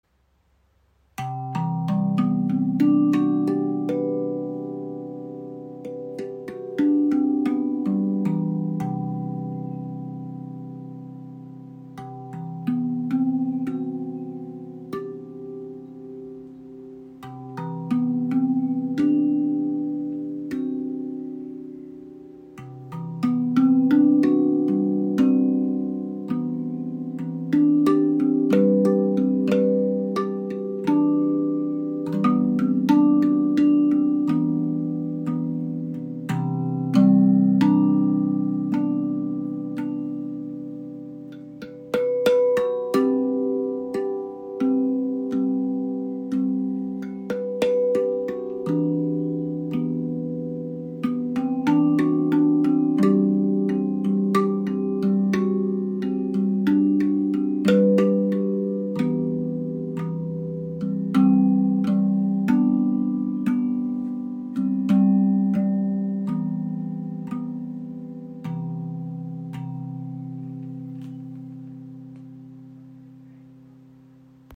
Erlebe die ARTQUINT Hybrid Drum – eine harmonische Verbindung aus Handpan und Zungentrommel. Warmer Klang, edles Design, verschiedene Stimmungen.
Klangbeispiel
Die C Golden Gate ist eine der hellsten und offensten Stimmungen der ARTQUINT Drum.
Verwandt mit der lydischen Tonleiter, bietet sie einzigartige Klangfarben, die besonders Gitarristen, Klangtüftler und Songwriter inspirieren. Ihr Charakter ist fliessend, kreativ und überraschend reich an Ausdruck.